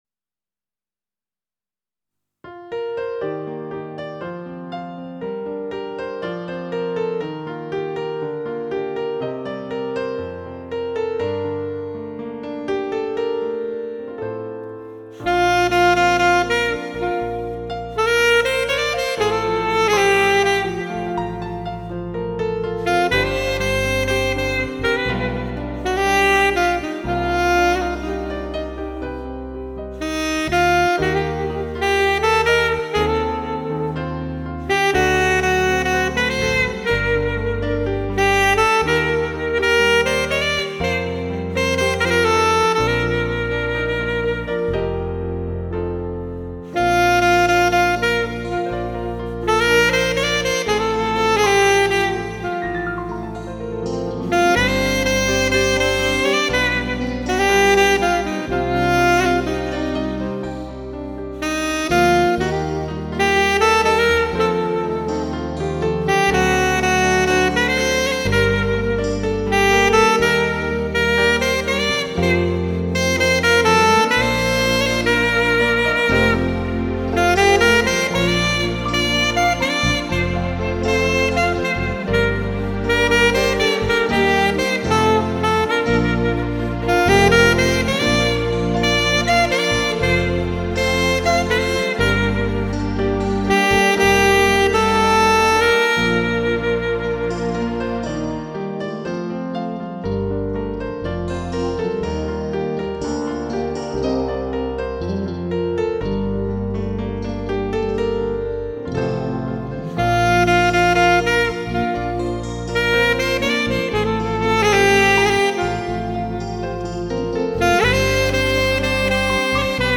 萨克斯，风中的音乐，蓝色的心声。